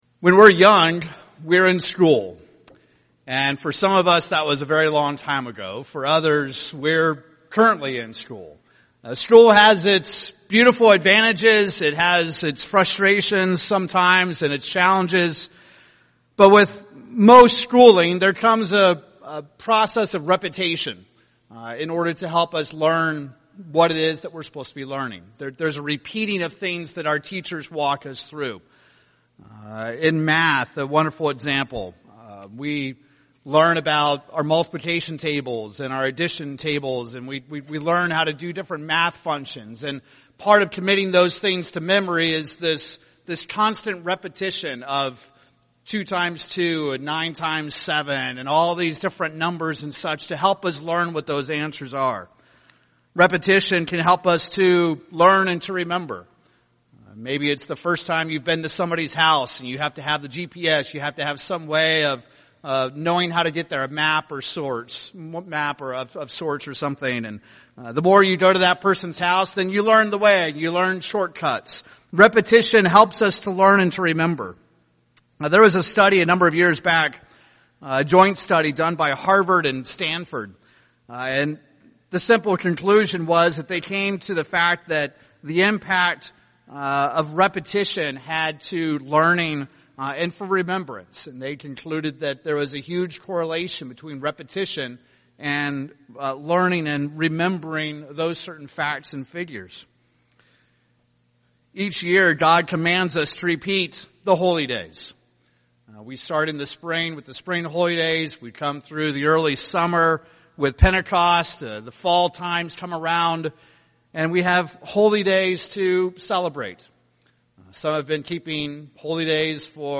Sermons
Given in Salina, KS Tulsa, OK Wichita, KS